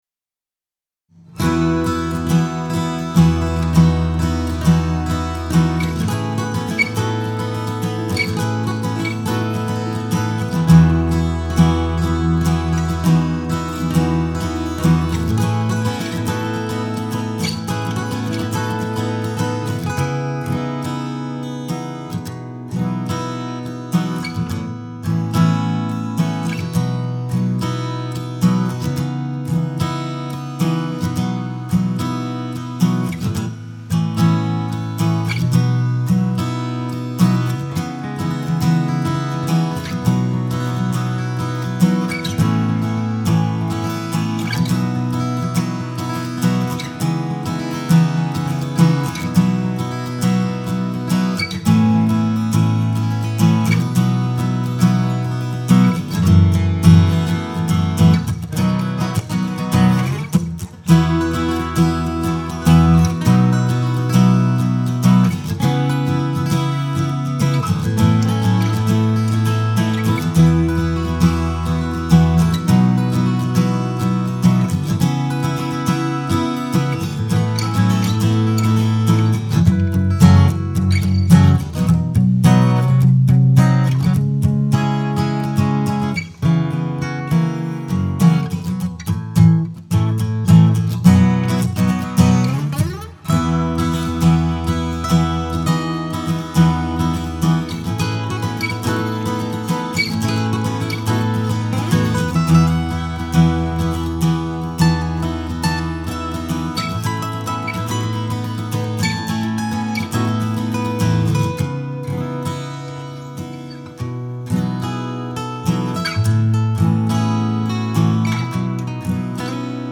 Les dejo una pequeña muestra de mi guitarra nueva… grabada con un condensador Shure KSM27 a traves de una Tascam FW-1082… fue practicamente a la primera y habia mucho ruido afuera de mi cuarto asi que no es la mejor calidad pero para que se den una idea… enjoy